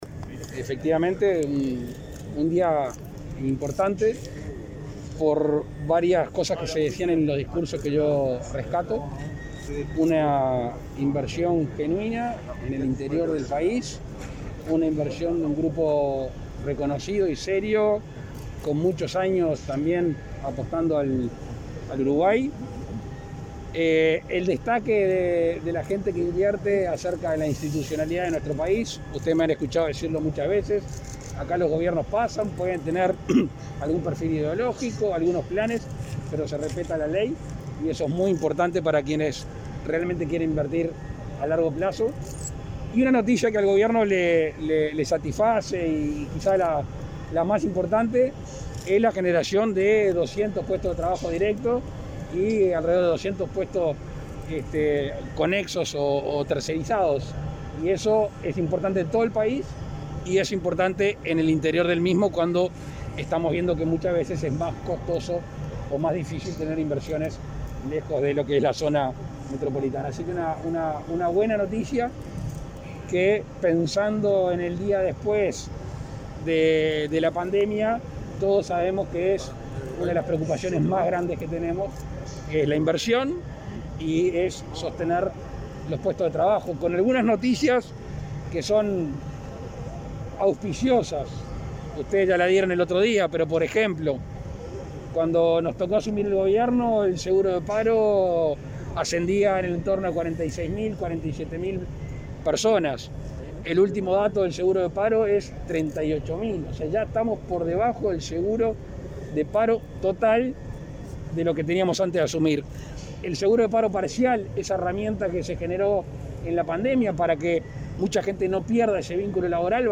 Declaraciones del presidente Luis Lacalle Pou a la prensa